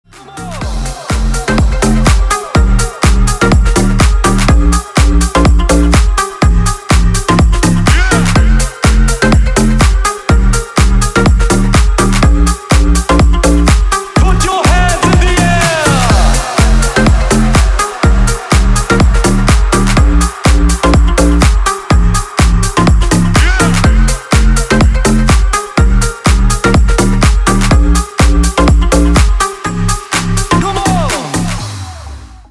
• Качество: 320, Stereo
remix
deep house
веселые
dance
EDM
house